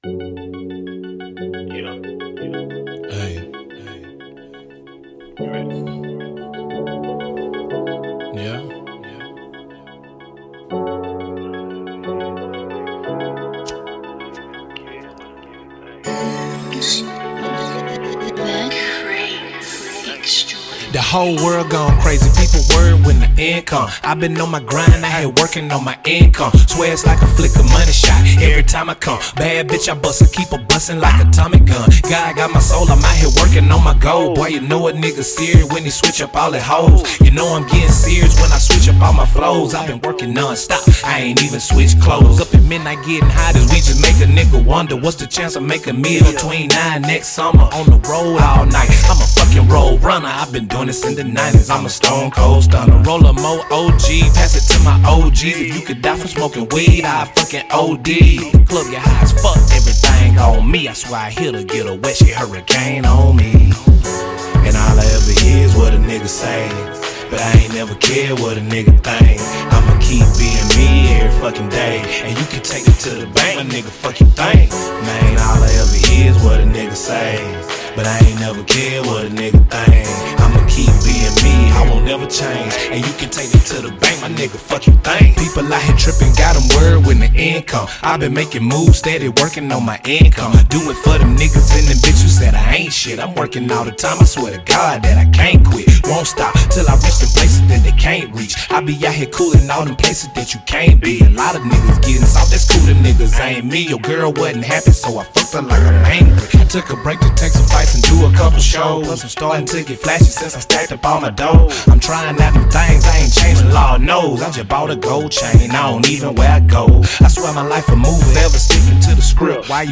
90 BPM